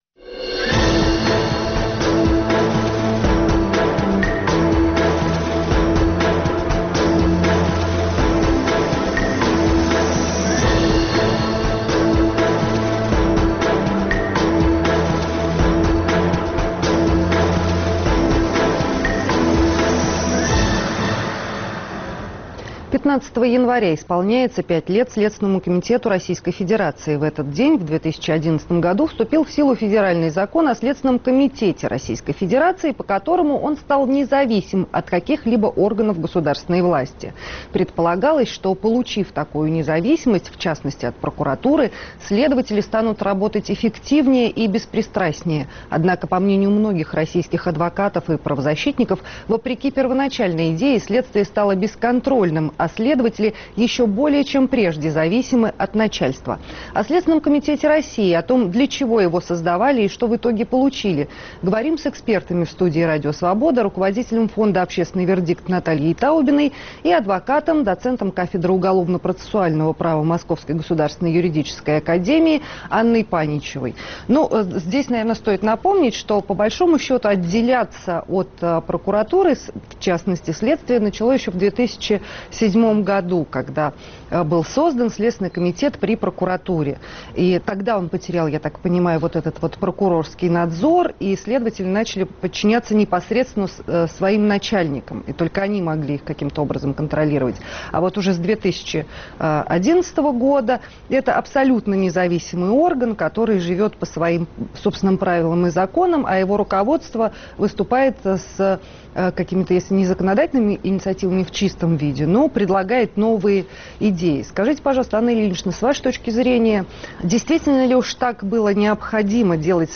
говорим с экспертами